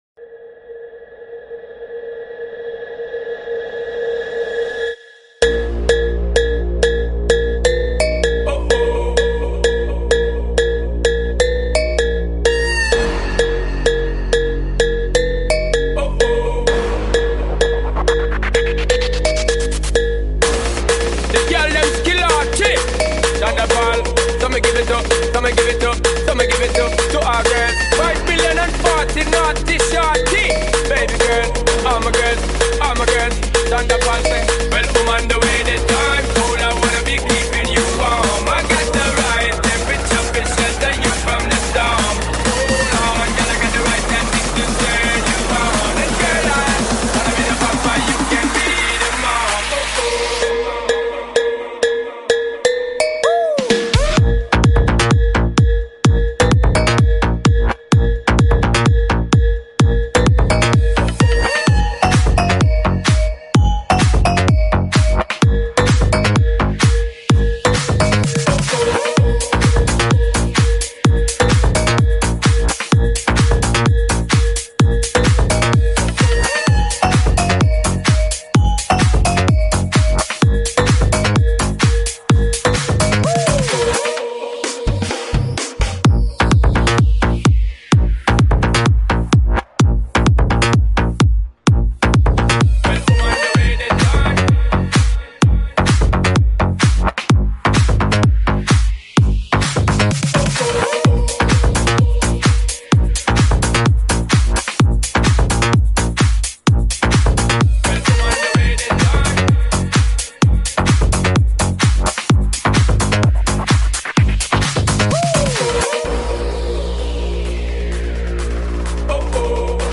🛫 From KLIA To Medan Sound Effects Free Download